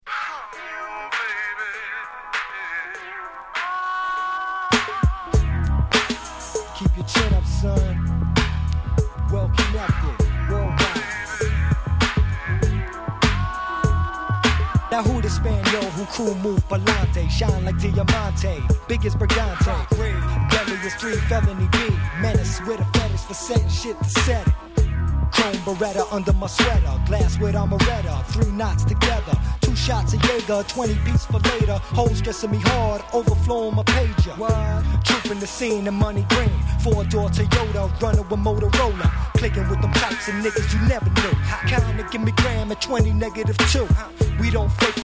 97' 人気Underground Hip Hop !!
このイナタイ感じ、まさに「アングラ」って感じで超格好良いですよね〜！！